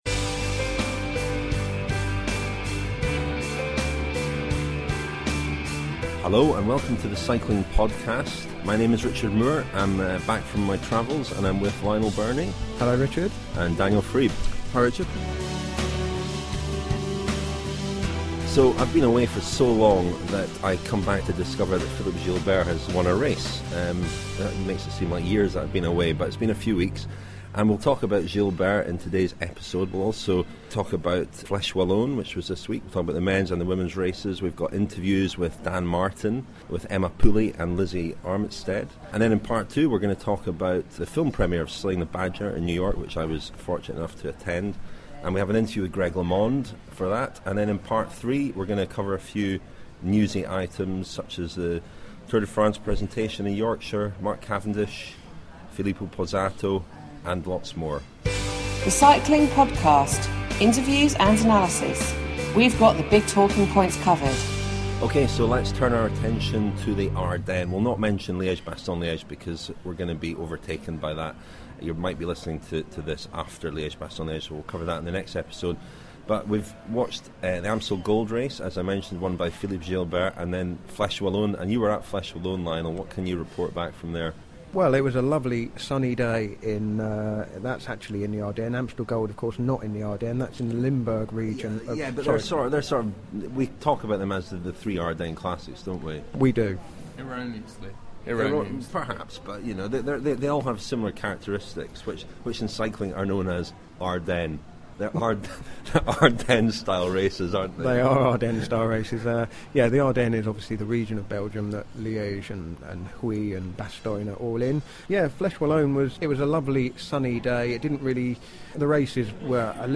Plus we hear from Lizzie Armitstead and Emma Pooley, who were second and seventh in the women's race.